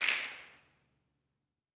SNAPS 16.wav